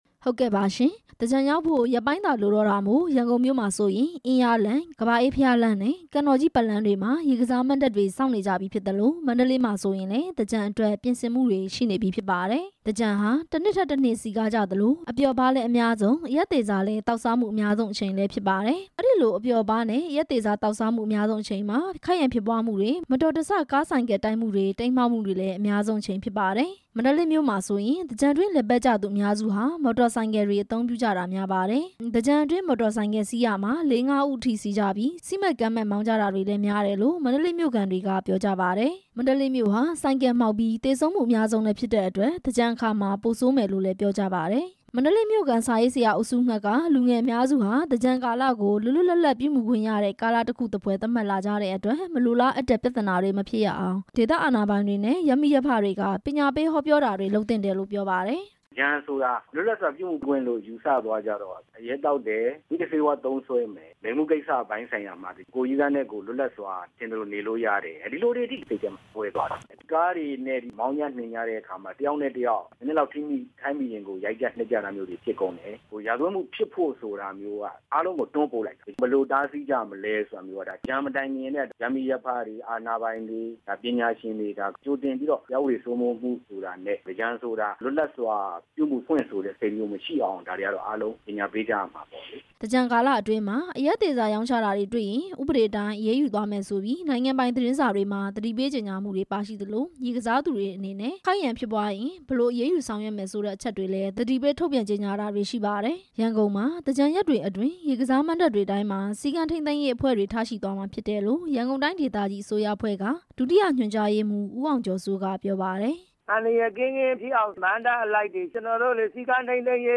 ပြည်သူတချို့ရဲ့ အမြင် နားထောင်ရန်